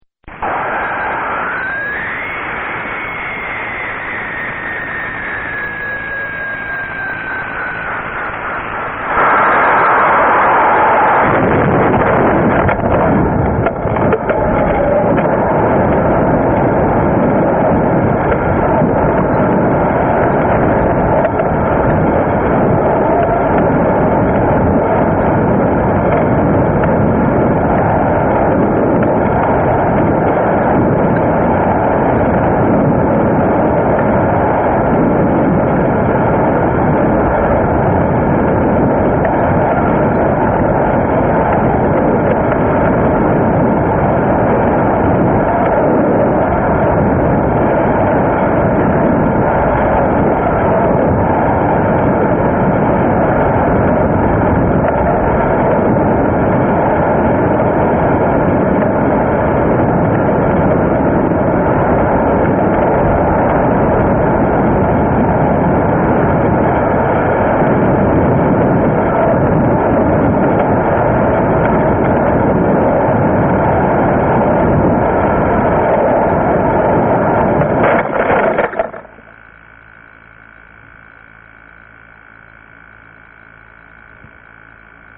Dans les 2 premières secondes, un soufflement dont la fréquence monte rapidement, signale la phase propulsée Ensuite le soufflement diminue régulièrement pendant la phase balistique . vers 10 secondes un fort bruit aérodynamique apparaît brusquement. C'est sans doute l'ouverture du ralentisseur 1 seconde plus tard on entend clairement l'ouverture des 2 parachutes principaux . Enfin, après une descente tranquille, rythmé par le bruit du parapluie battant dans le vent (comme il n'est pas dans le sens de la descente, le vent a tendance à le refermer). L'atterrissage intervient vers 80 secondes.